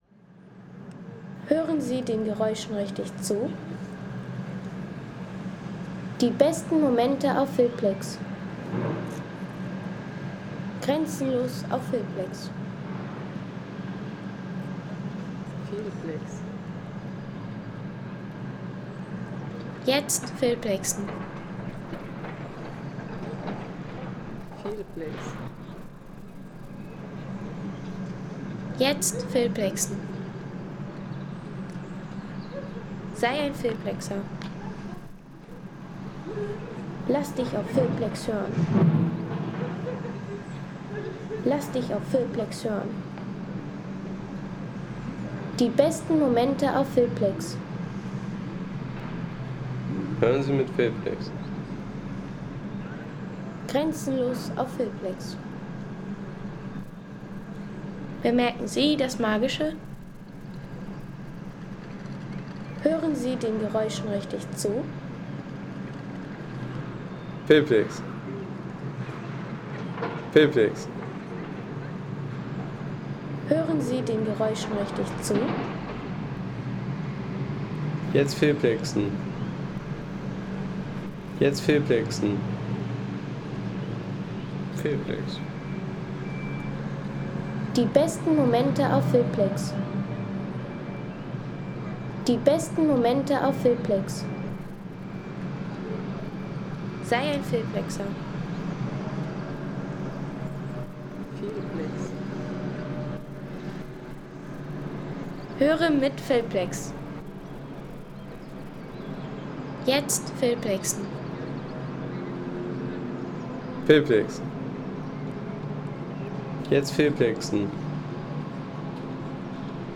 Straßenbau im Barockstadtviertel von Fulda